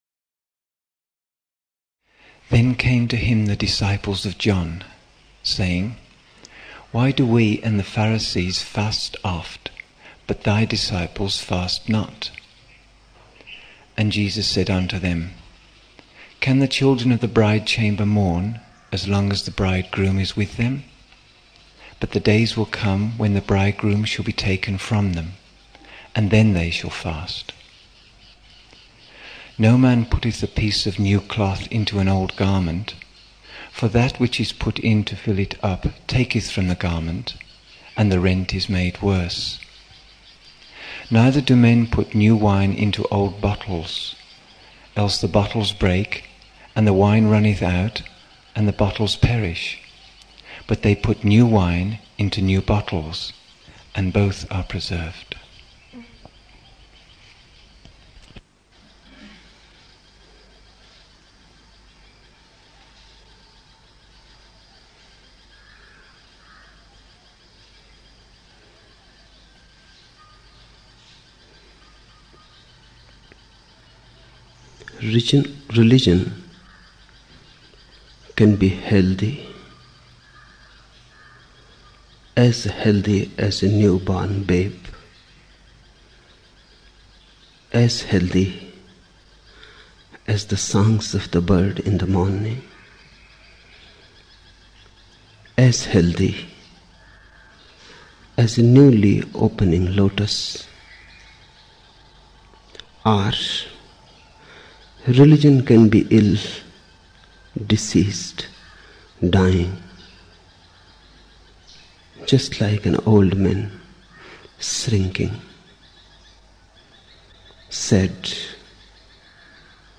31 October 1975 morning in Buddha Hall, Poona, India